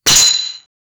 atack-with-axe-breaking-t-o6ixk2ok.wav